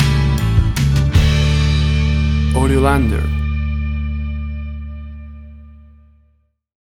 WAV Sample Rate: 16-Bit stereo, 44.1 kHz
Tempo (BPM): 155